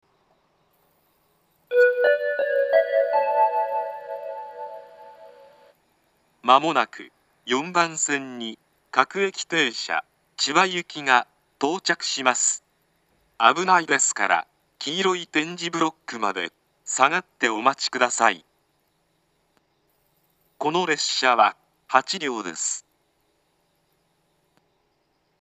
２０１７年１月１８日には放送装置が更新され、自動放送が合成音声に変更されました。
４番線接近放送
音程は低いです。